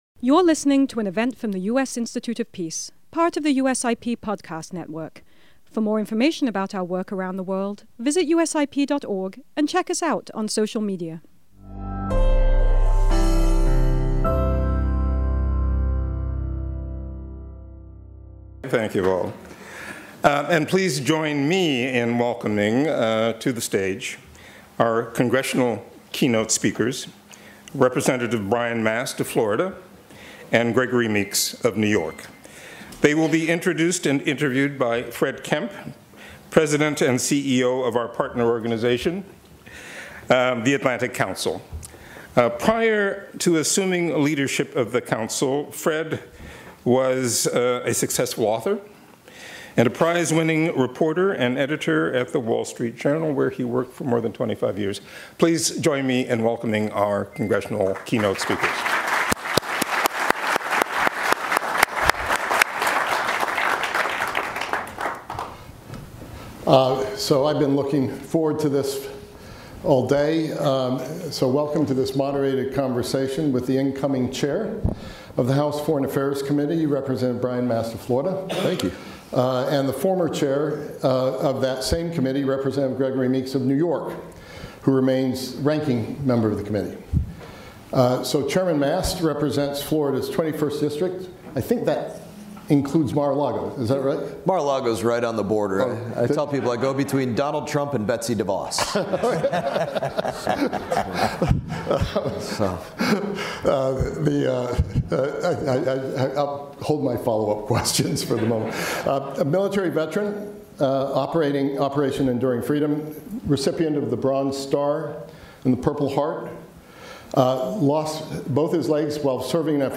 This flagship, bipartisan event brought together national security leaders from across the political spectrum to mark the peaceful transfer of power and the bipartisan character of American foreign policy.